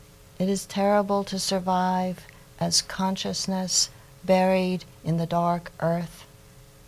Here is Glück, whose reading style is often mentioned as an example of Poet Voice, reading the third stanza of “The Wild Iris” (1992): “It is terrible to survive / as consciousness / buried in the dark earth”: